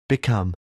6. become (v.) /bi’kʌm/ trở thành, trở nên